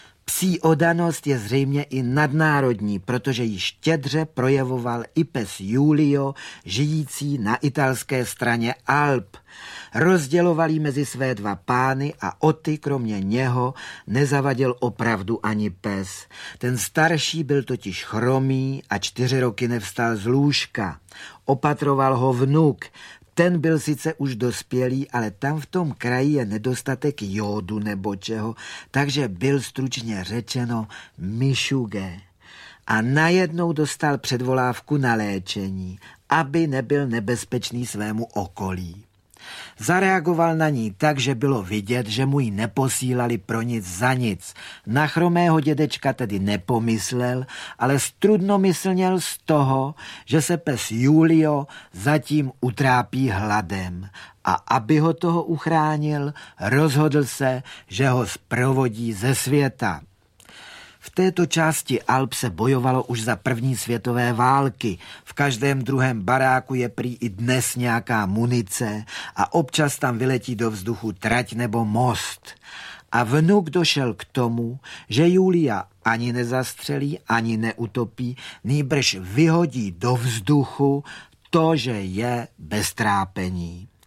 Audio kniha
• InterpretFrantišek Nepil